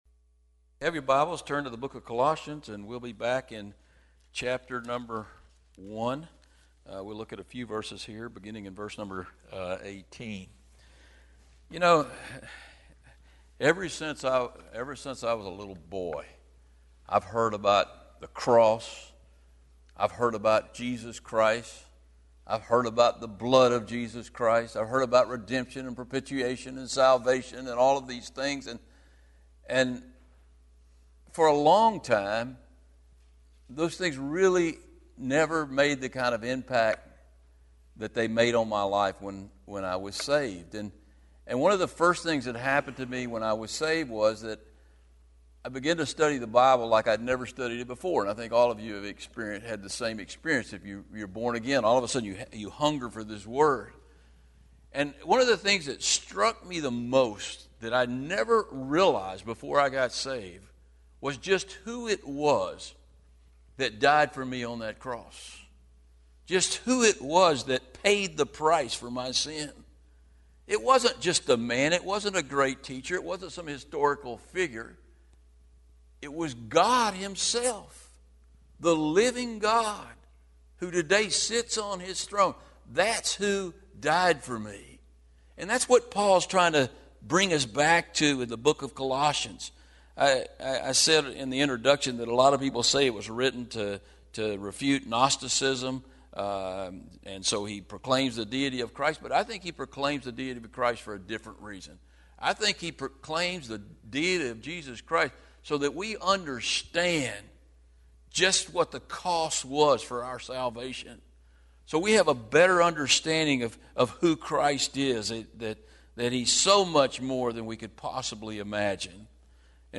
These teachings on Colossians are from Wednesday evening service.